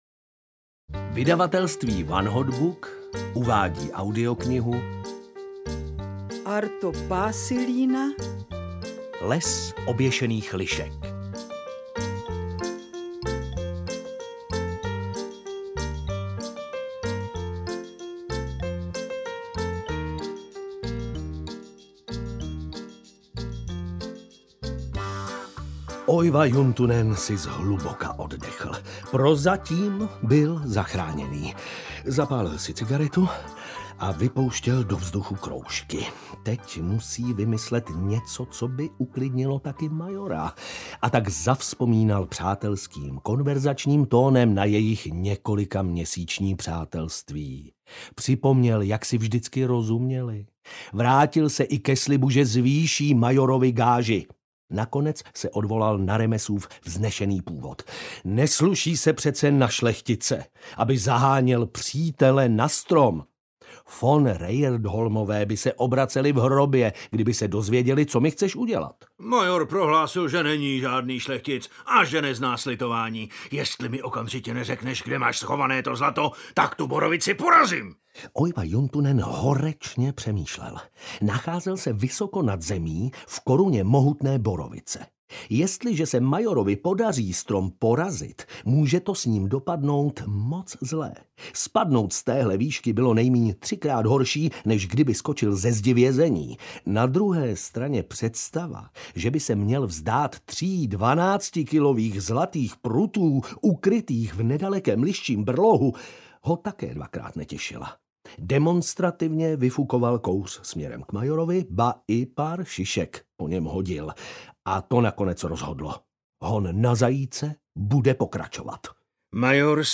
Les oběšených lišek audiokniha
Ukázka z knihy